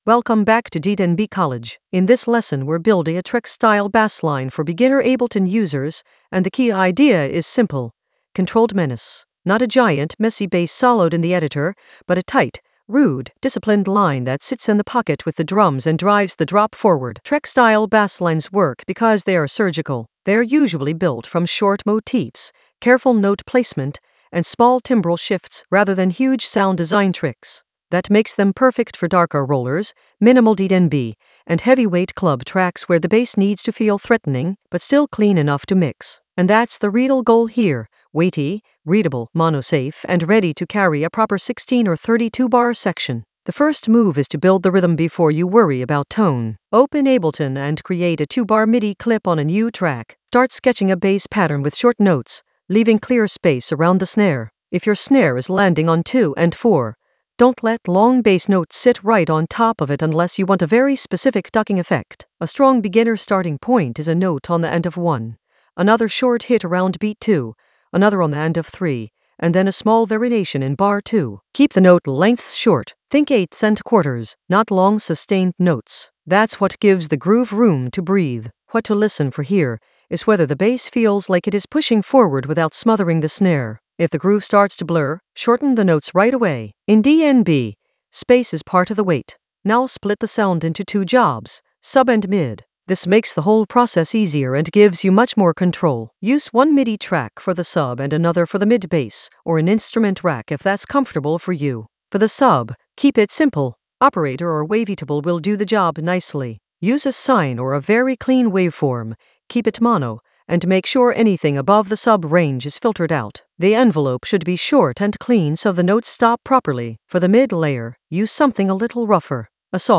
Narrated lesson audio
The voice track includes the tutorial plus extra teacher commentary.
An AI-generated beginner Ableton lesson focused on Trex style basslines in the Basslines area of drum and bass production.